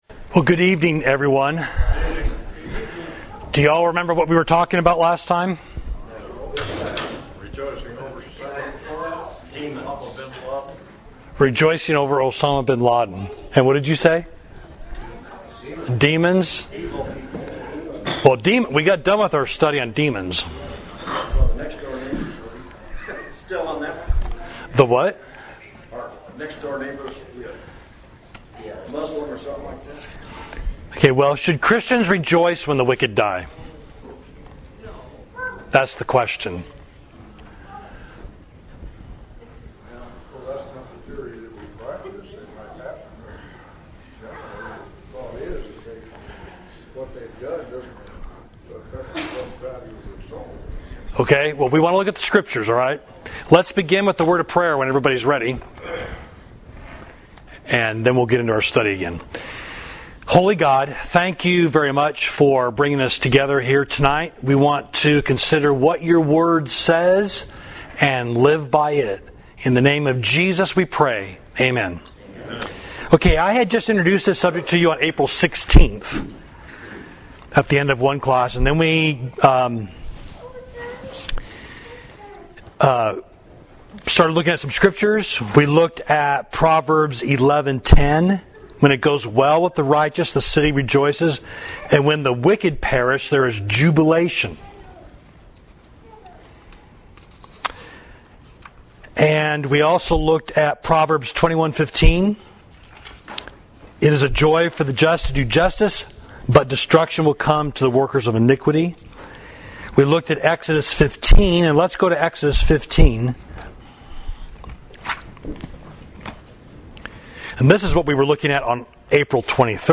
Class: Should Christians Rejoice When the Wicked Die?